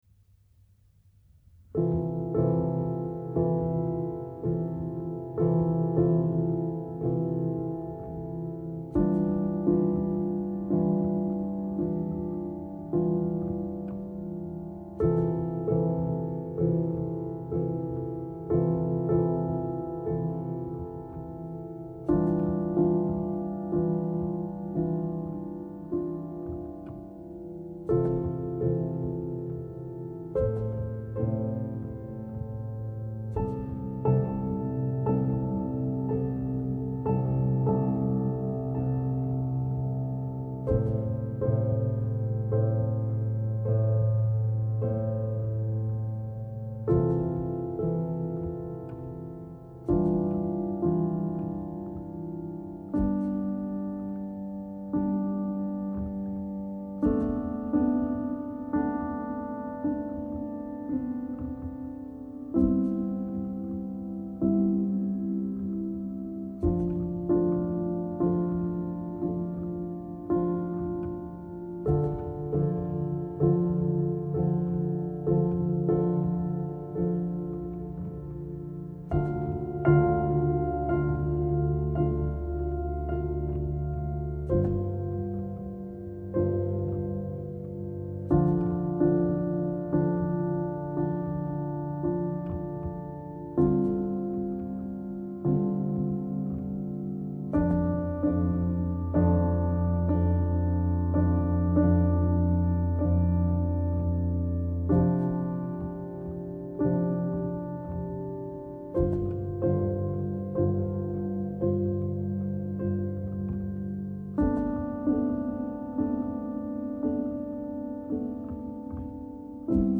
International Publisher and label for New experimental Music
piano